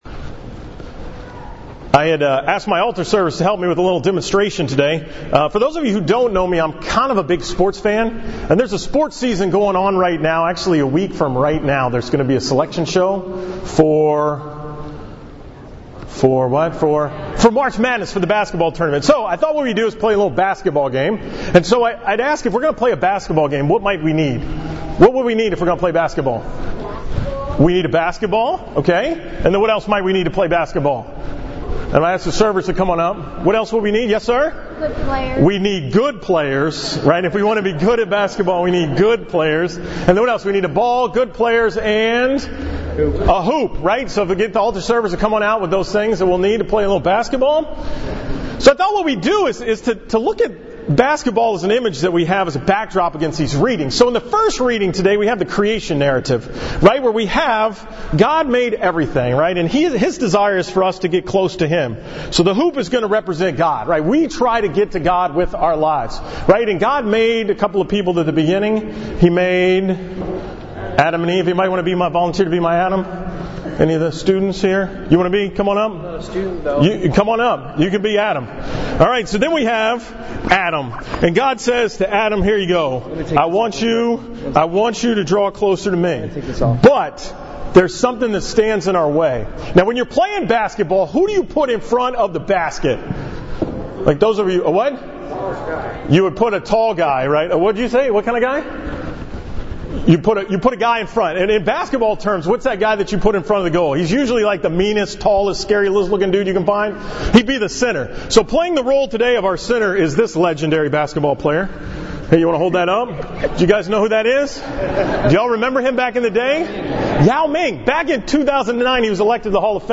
From the 1st Sunday of Lent on March 5, 2017 at St. Anne's in Houston.